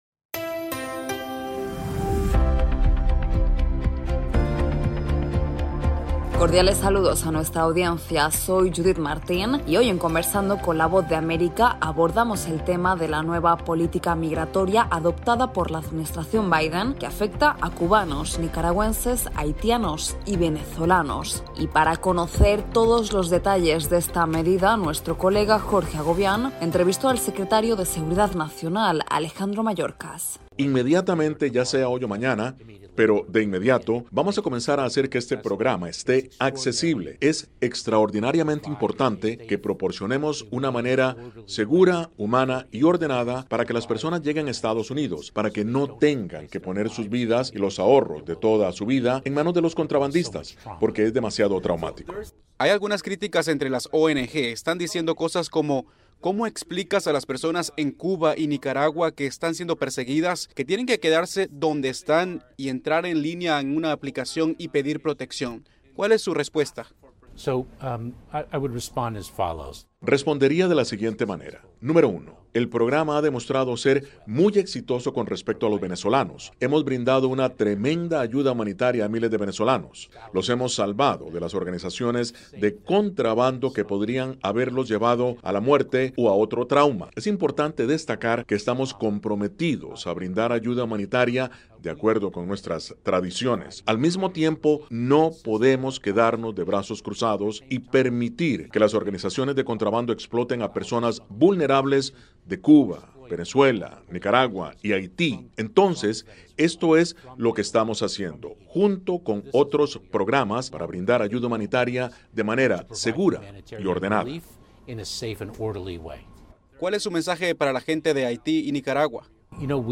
Conversamos con Alejandro Mayorkas el secretario de Seguridad Nacional de Estados Unidos sobre la nueva medida migratoria adoptada por la Administración Biden y que afecta a ciudadanos de Venezuela, Cuba, Nicaragua y Haití.